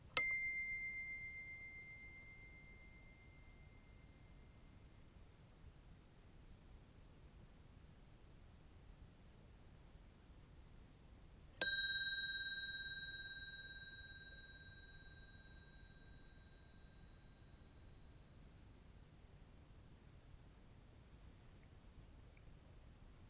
wuc_bell_high_and_low
bell clock high-pitched wind-up wind-up-clock sound effect free sound royalty free Nature